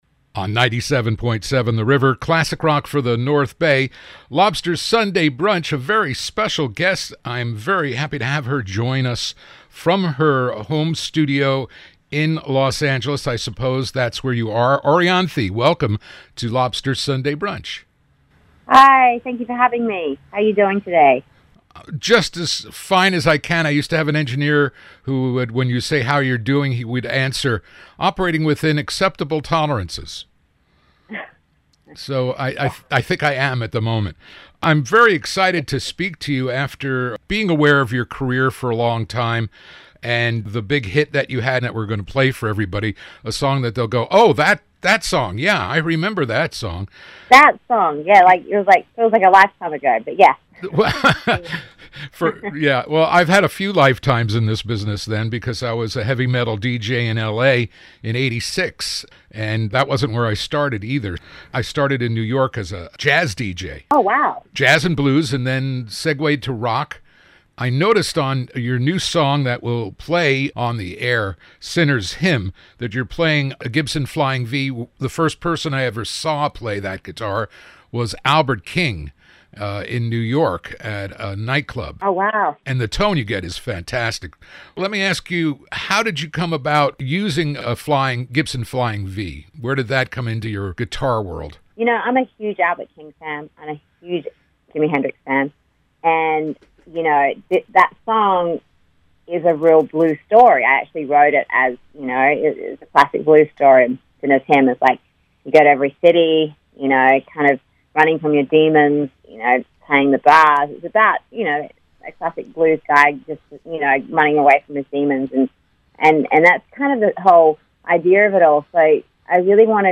Orianthi-Complete-Interview.mp3